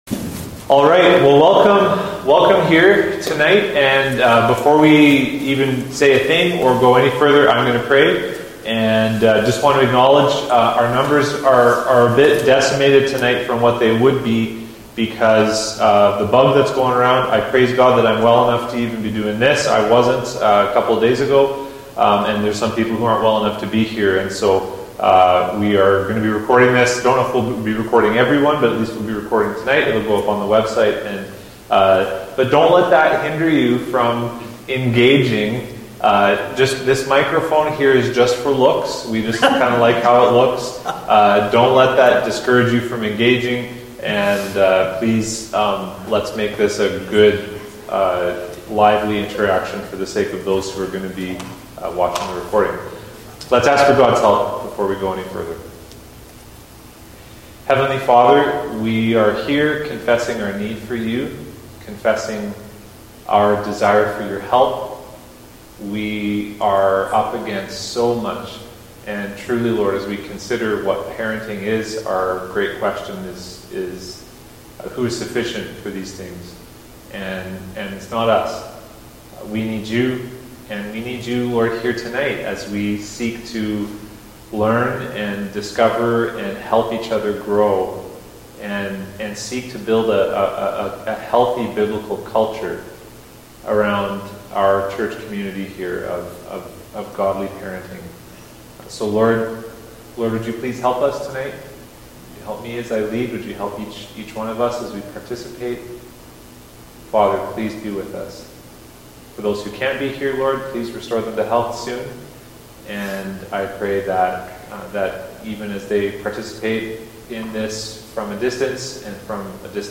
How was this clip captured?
Unfortunately the video cut off a few minutes before our session ended.